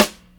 Boom Bap Wonderful Snare.wav